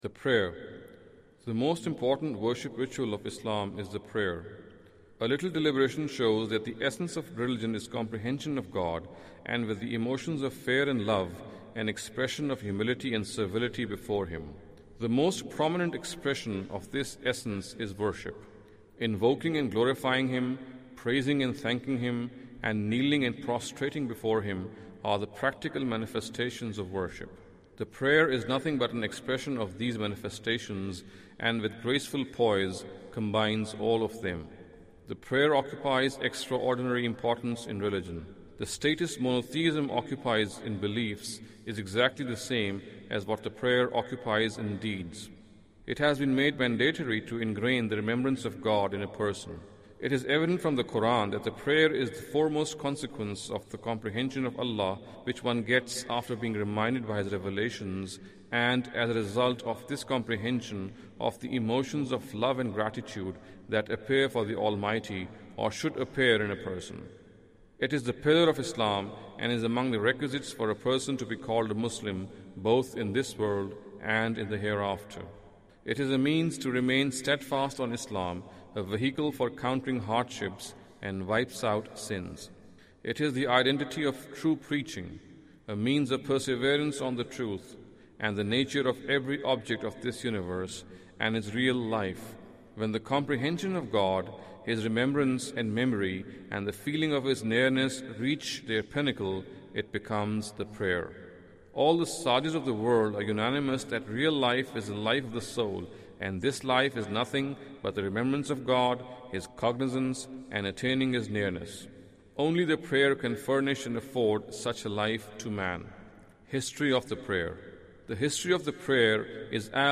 Audio book of English translation of Javed Ahmad Ghamidi's book "Islam a Concise Intro".